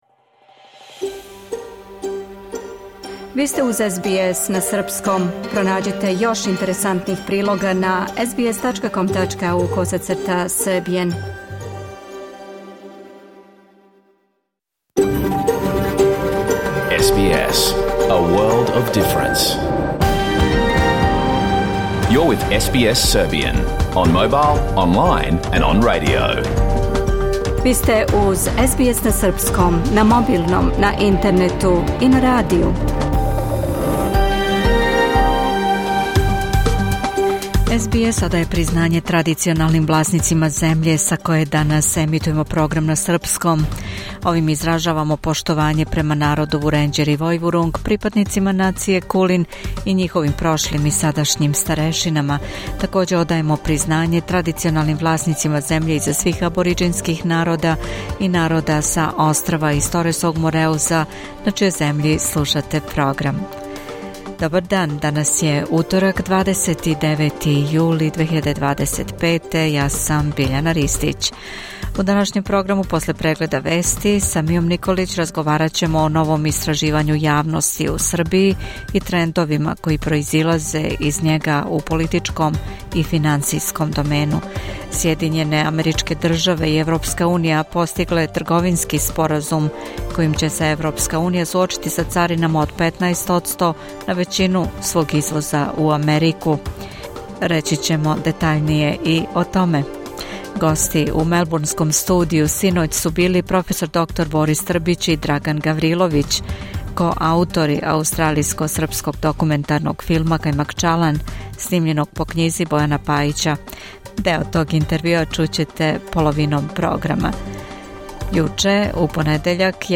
Програм емитован уживо 29. јула 2025. године